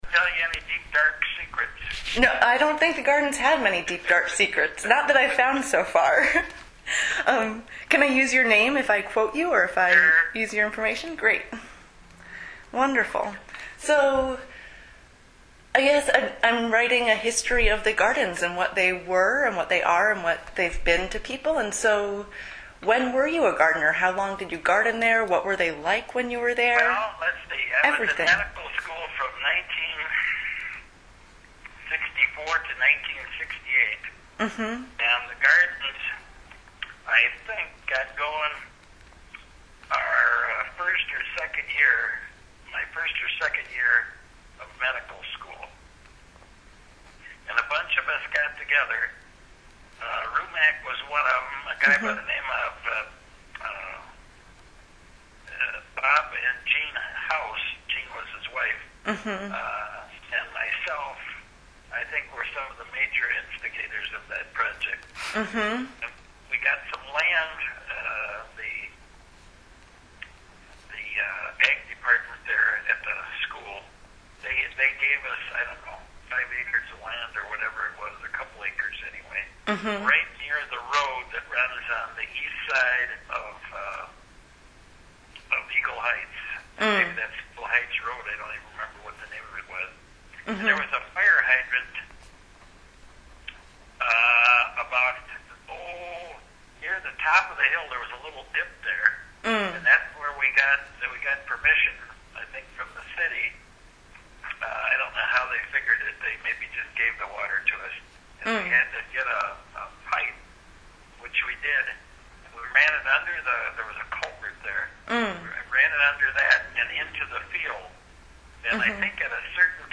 Oral History Interview